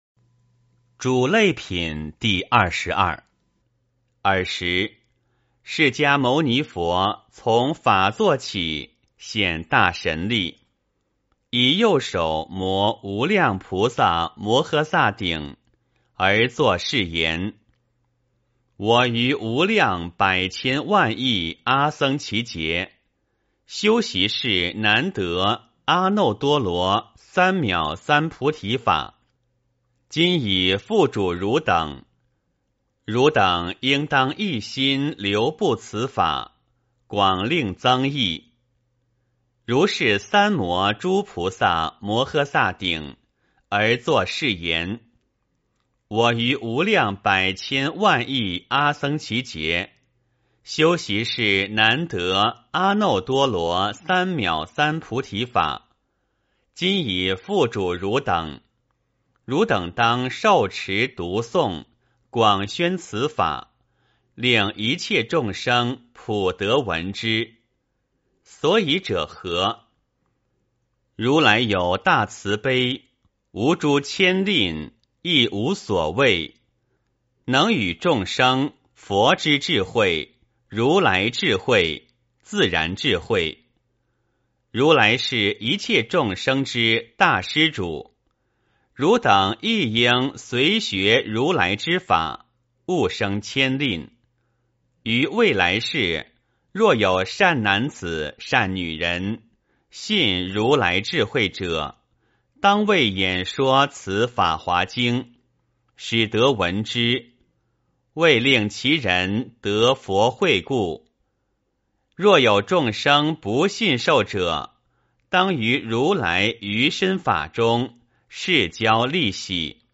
法华经-嘱累品第二十二 诵经 法华经-嘱累品第二十二--未知 点我： 标签: 佛音 诵经 佛教音乐 返回列表 上一篇： 法华经-法师功德品第十九 下一篇： 法华经-妙音菩萨品第二十四 相关文章 春风微雨的绿精灵--王俊雄 春风微雨的绿精灵--王俊雄...